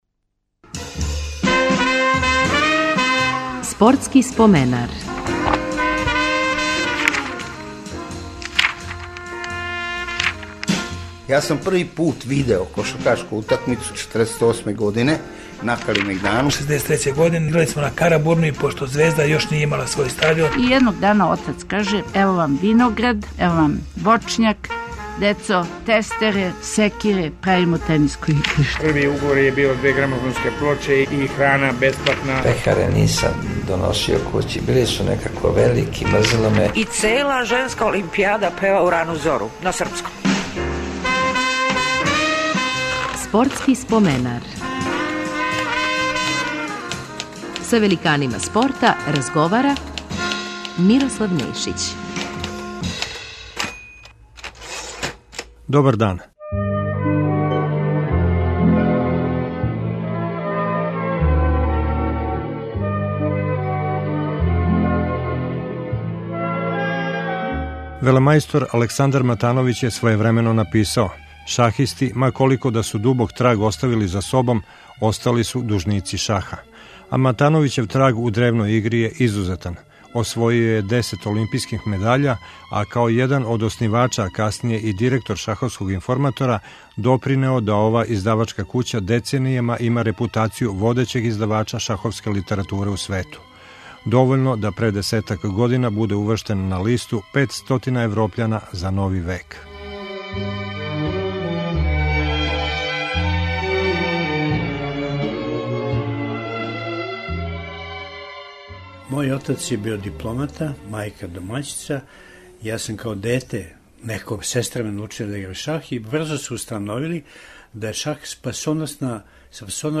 Гост емисије биће шахиста велемајстор Александар Матановић. Био је члан државног тима у време највећих успеха југословенског шаха.